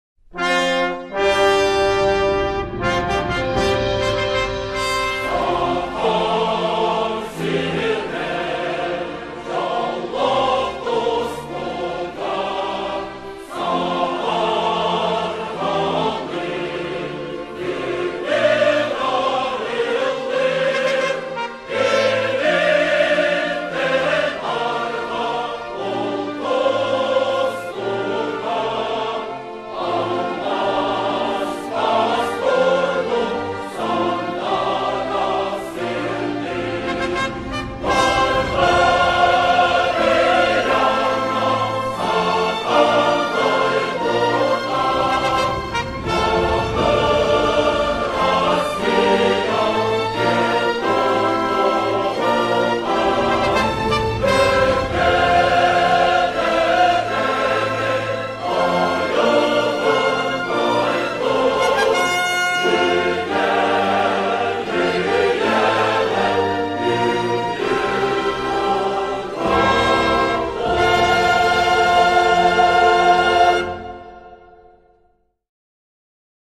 в исполнении хора а капелла